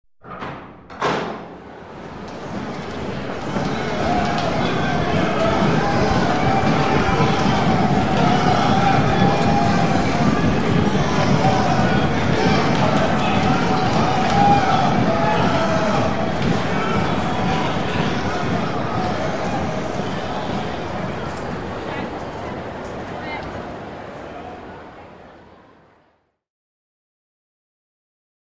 Players, Push Bar Door Open, Walla, Foot Steps By In Tunnel. Could Use For Various Sports. Room Reverb.